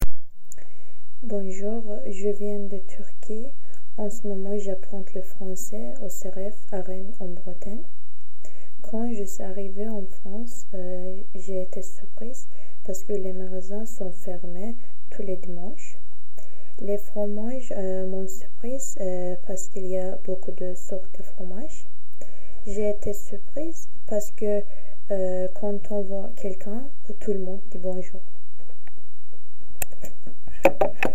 Cabine de témoignages
Témoignage du 24 novembre 2025 à 17h07